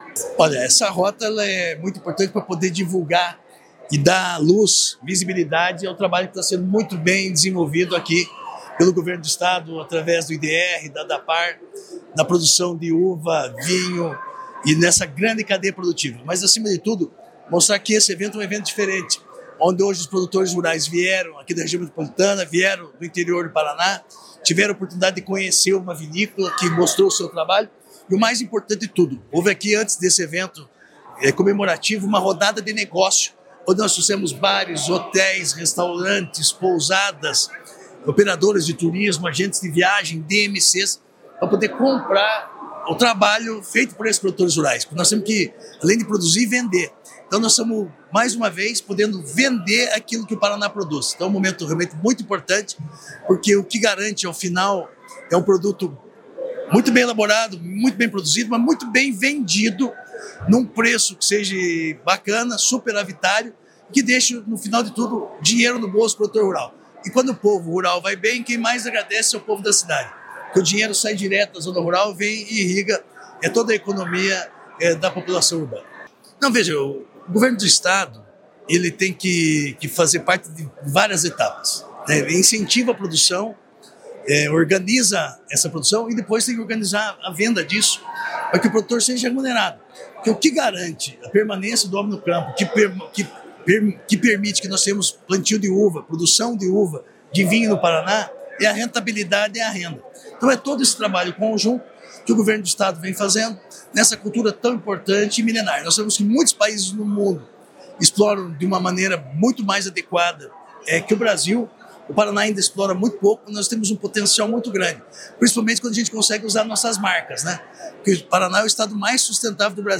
Sonora do secretário da Agricultura e do Abastecimento, Márcio Nunes, sobre a Rota da Uva & Vinho